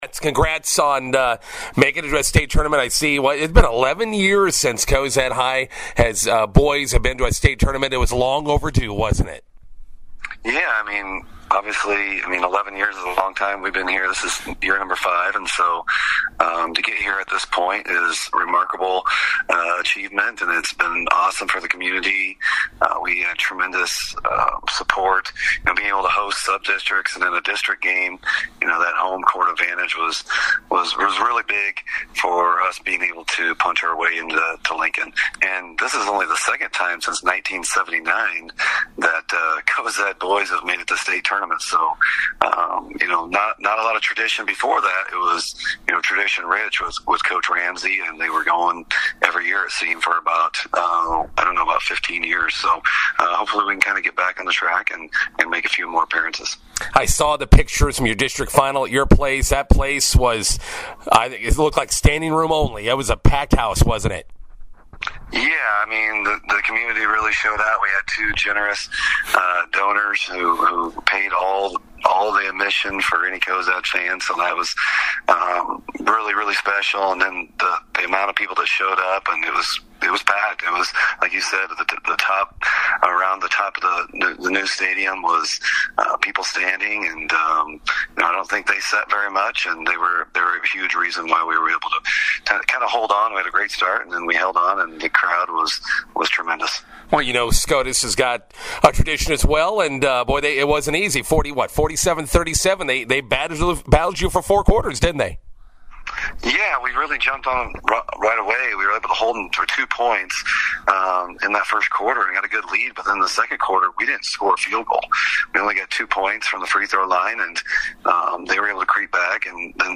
INTERVIEW: Cozad boys basketball back at the state tournament for the first time since 2014.